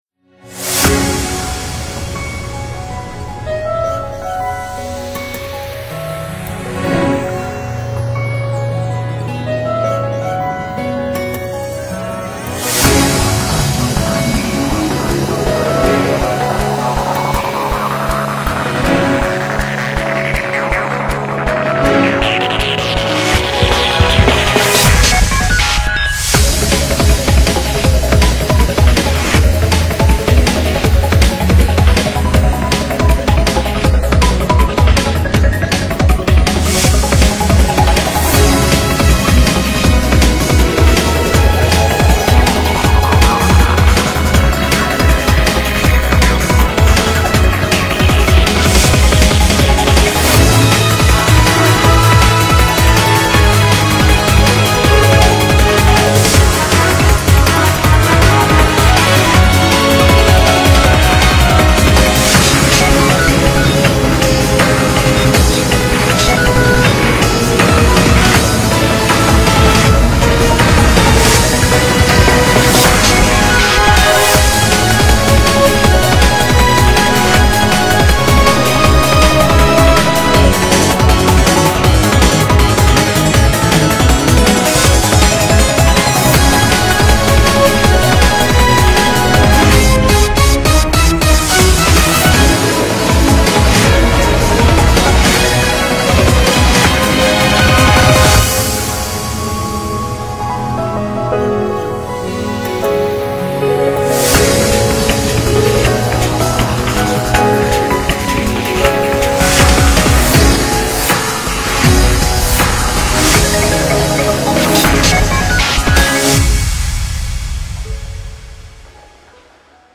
BPM80-160
Genre: Chaos fantasia.